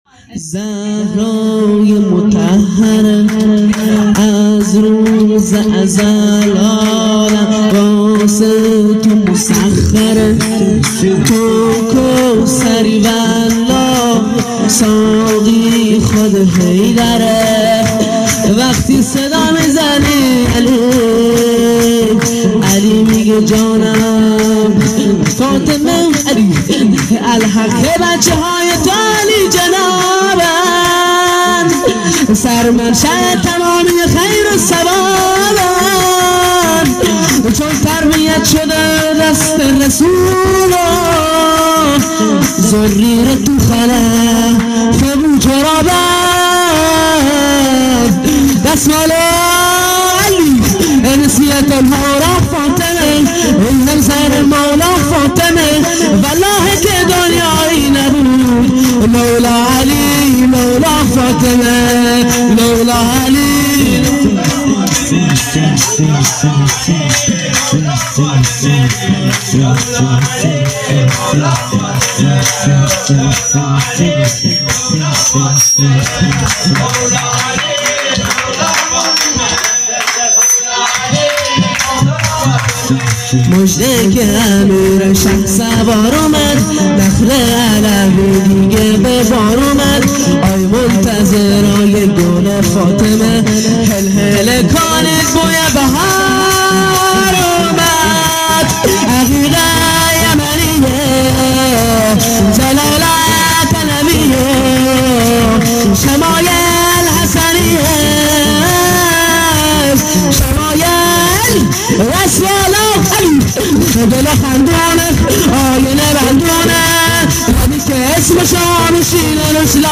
جشن ها
هیئت مذهبی منتظران موعود بندرعباس